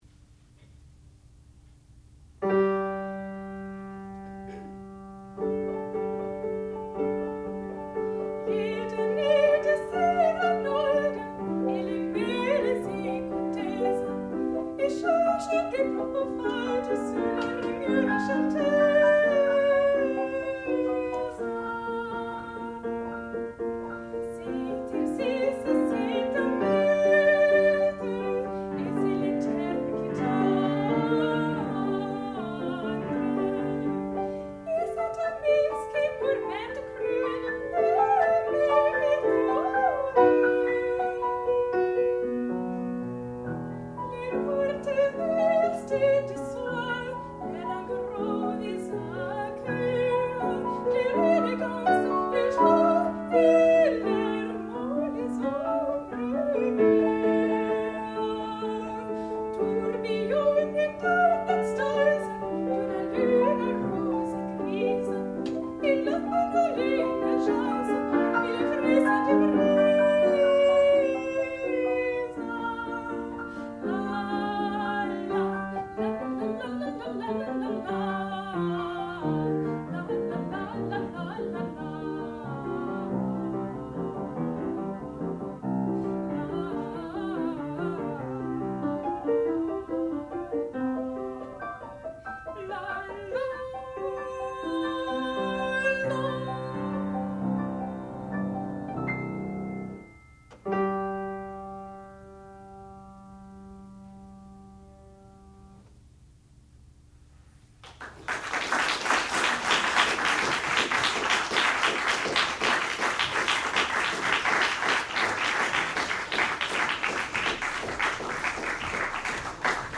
sound recording-musical
classical music
piano
soprano
Student Recital